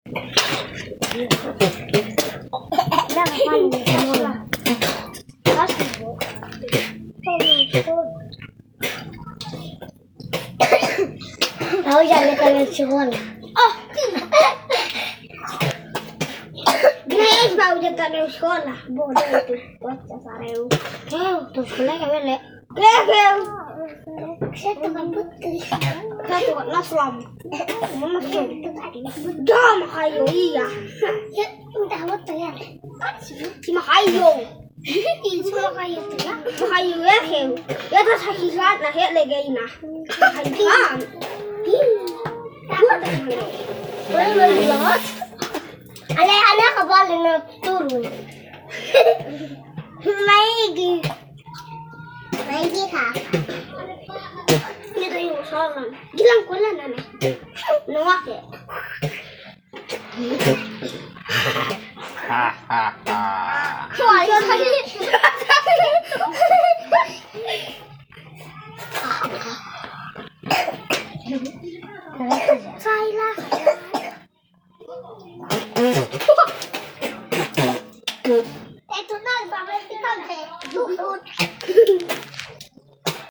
Immerse yourself in the joyful ambiance of Madobag village, Mentawai Islands, where children's laughter and playful shouts fill the air. This soundscape captures the carefree moments of youngsters gathered around an ancient well, their giggles and splashing sounds blending with the serene atmosphere of the surrounding environment.